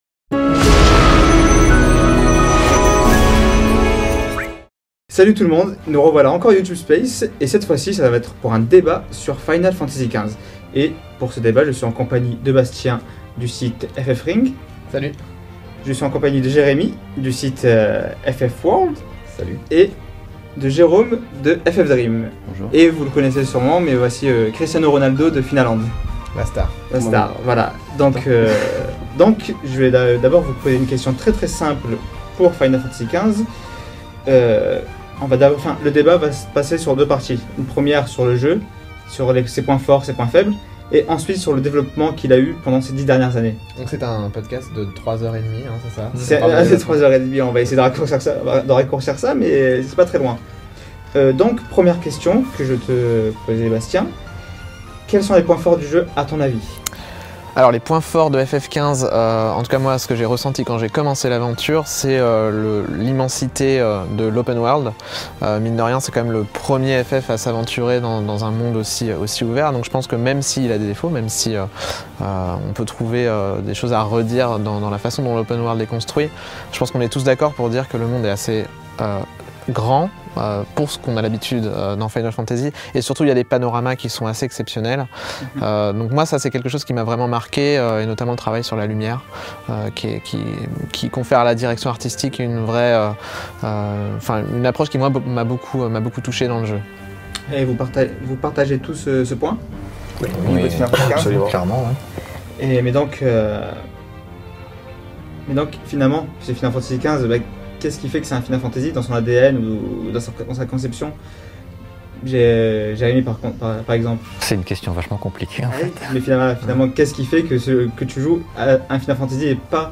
Quelques mois après la sortie de Final Fantasy XV , nous avons réuni les sites communautaires français de Final Fantasy afin de débattre sur ce nouvel épisode de notre saga préférée.